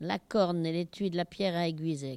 Localisation Barbâtre
Catégorie Locution